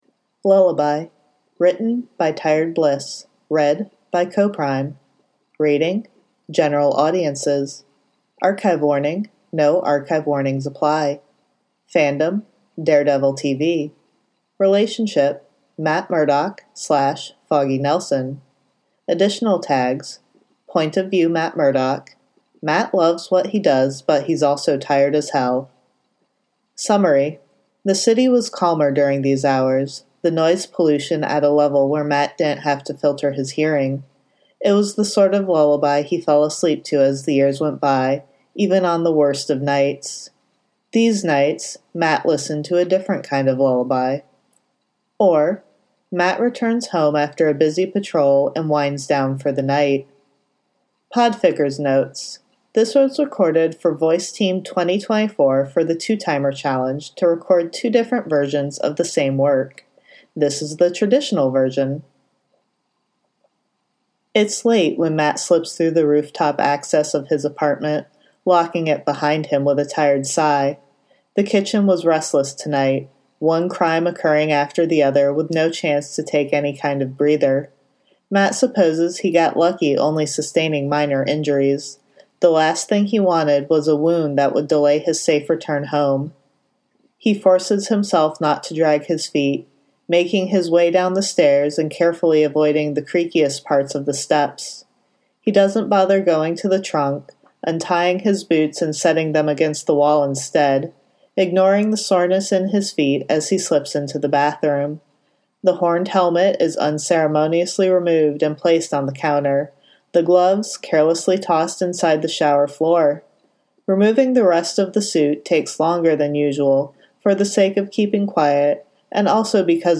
One fic with two podfic versions.
My traditional style: